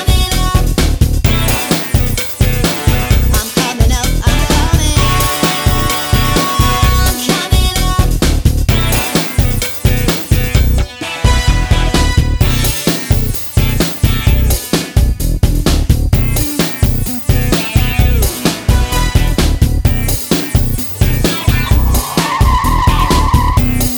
plus extra chorus Backing Vocals Rock 3:13 Buy £1.50